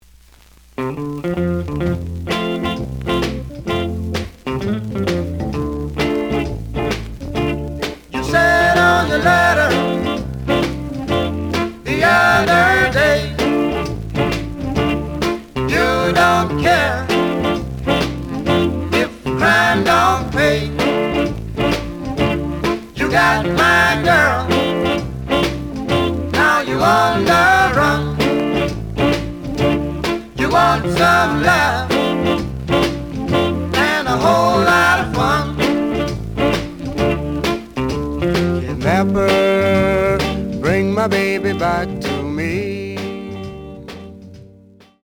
The audio sample is recorded from the actual item.
●Genre: Rhythm And Blues / Rock 'n' Roll
Slight affect sound.